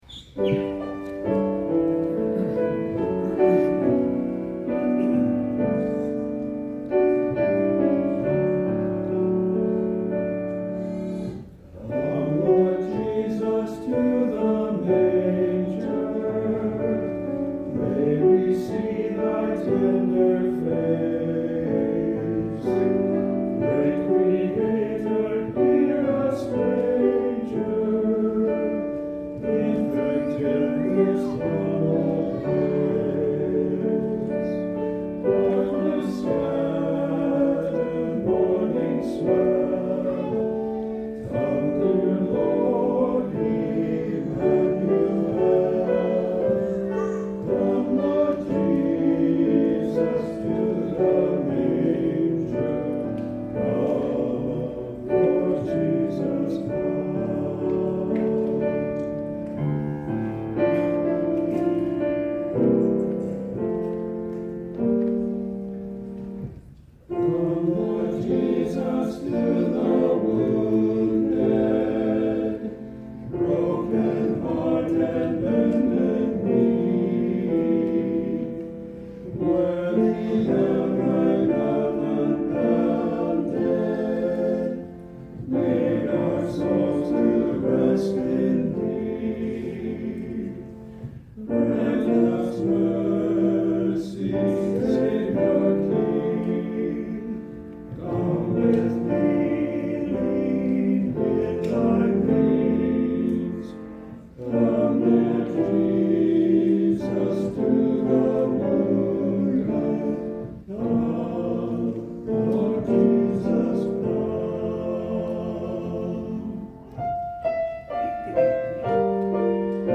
Voicing/Instrumentation: TTBB